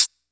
Hat (11).wav